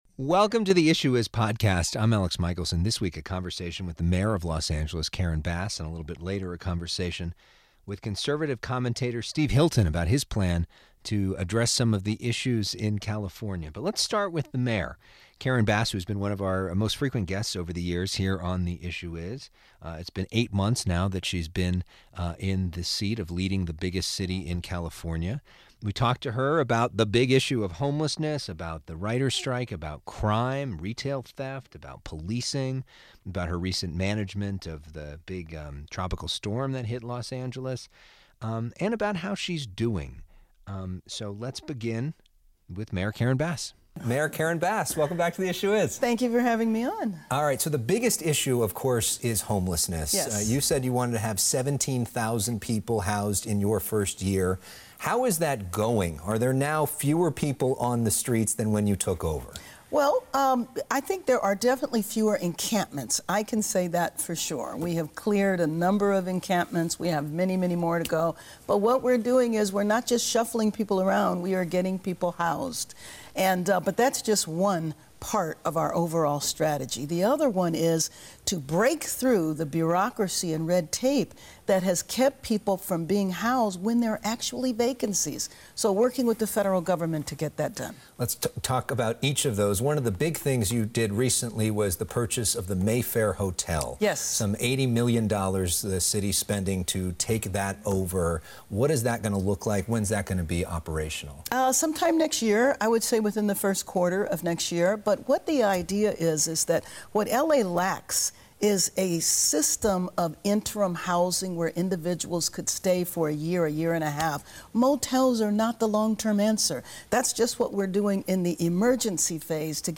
Then, he is joined by Fox News Contributor Steve Hilton to discuss his efforts to save California.